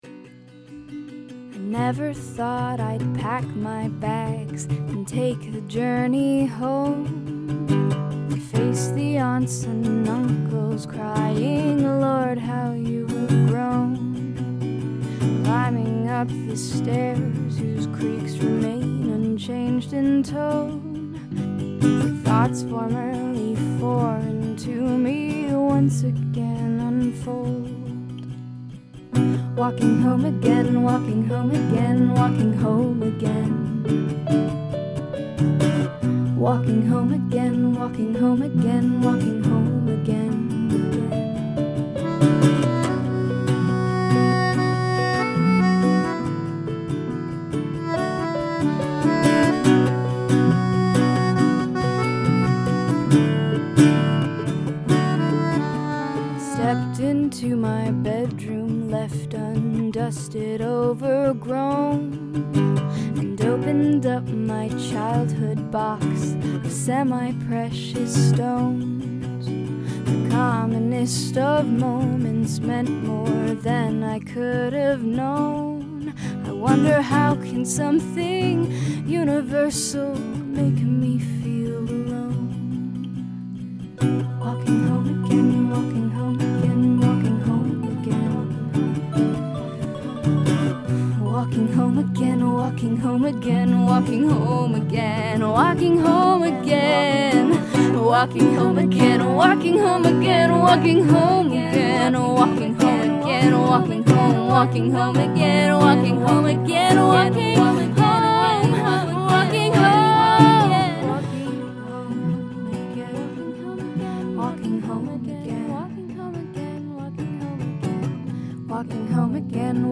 Gradual emphasis of repetitions